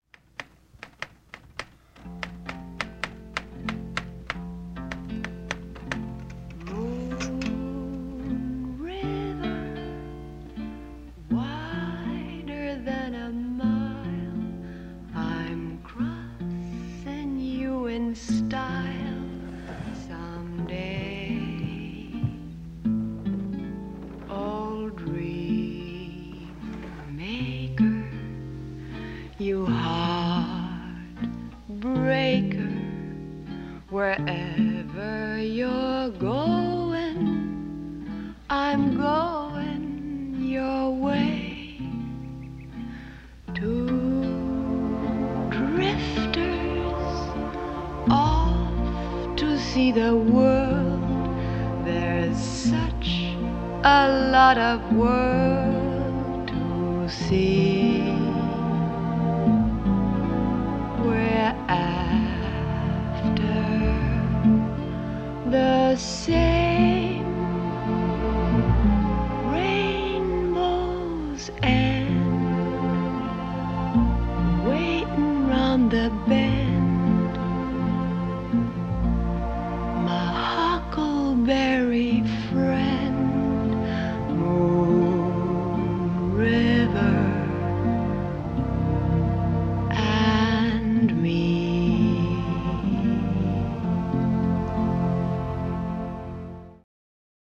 是从DVD里截取的，就是女主吉他伴奏清唱的那个片段，还包含了男主角打字的声音。